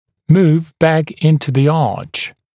[muːv bæk ‘ɪntə ðə ɑːʧ][му:в бэк ‘интэ зэ а:ч]переместить обратно в зубной ряд, вернуться в зубную дугу